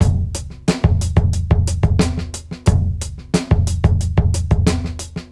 Index of /musicradar/sampled-funk-soul-samples/90bpm/Beats
SSF_DrumsProc2_90-04.wav